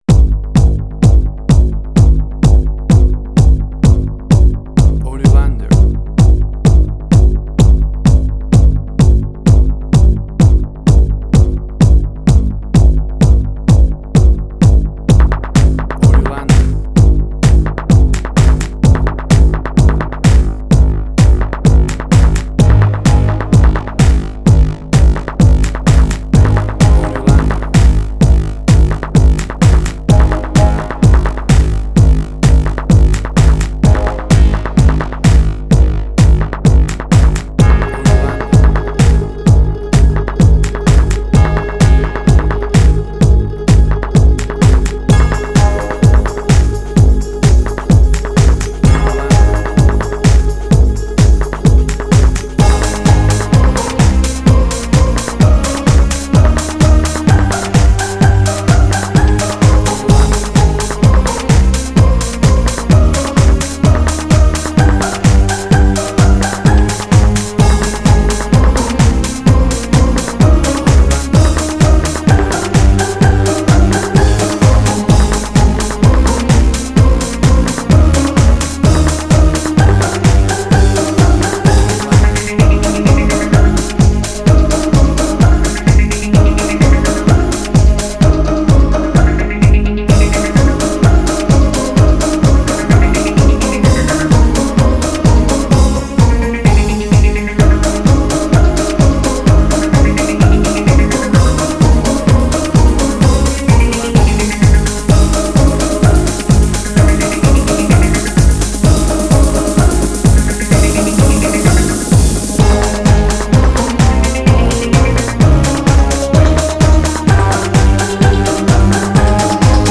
Jewish Techno Trance.
Tempo (BPM): 128